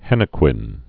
(hĕnĭ-kwĭn)